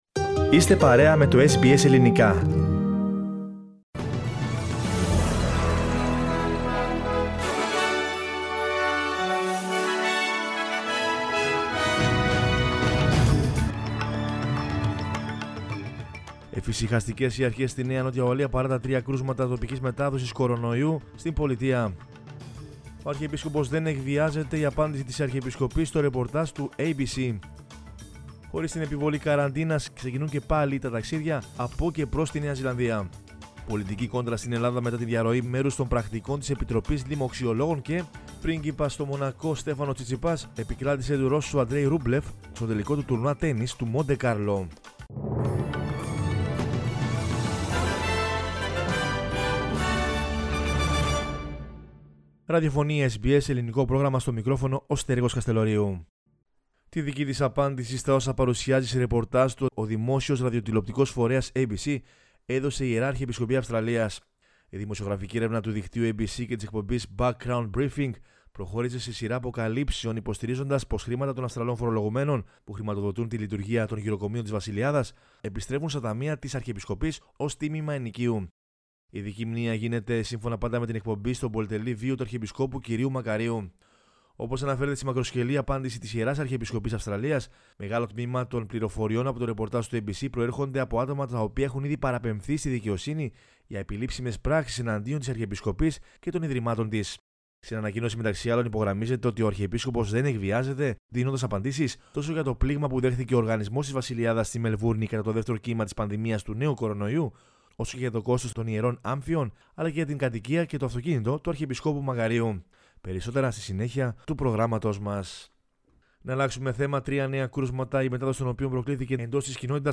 News in Greek from Australia, Greece, Cyprus and the world is the news bulletin of Monday 19 April 2021.